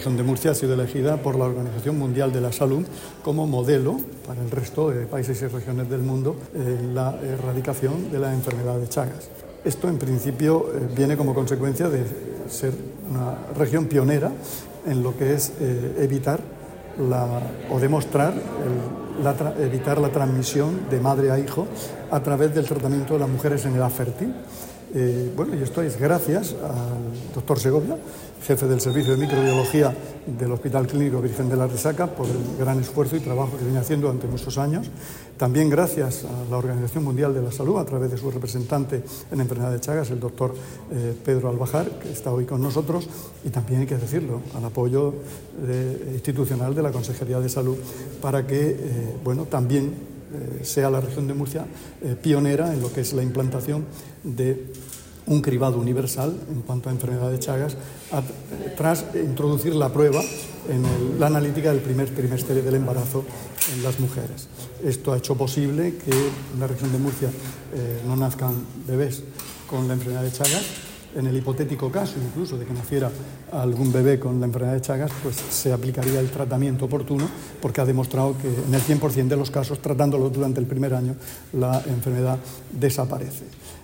Sonido/ Declaraciones del consejero de Salud, Juan José Pedreño, sobre la interrupción de la transmisión de la enfermedad de Chagas en la Región.